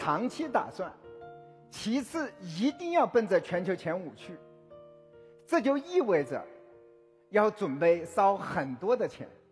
Exploitez le ton charismatique et autoritaire de Lei Jun pour vos présentations technologiques, documentaires d'entreprise et contenus de motivation.
Voix IA de Lei Jun